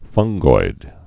(fŭnggoid)